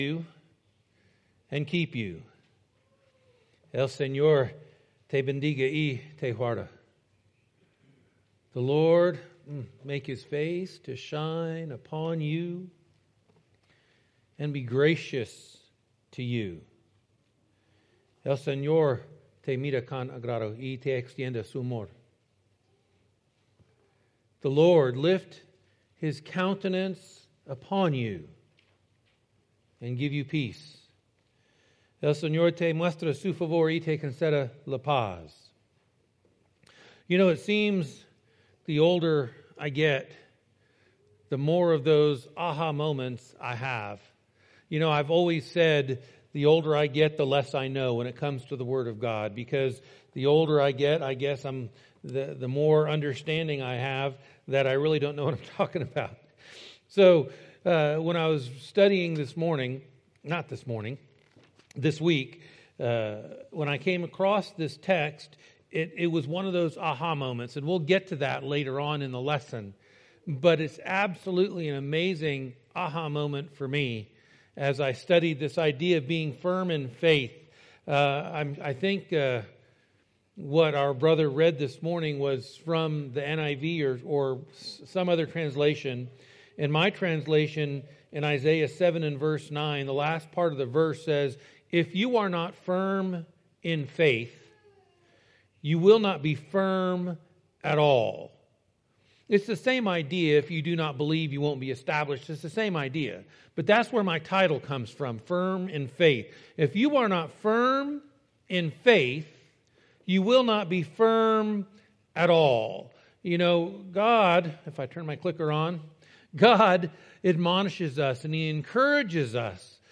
Jul 5, 2020 | by Sample List | series: Sample Sermon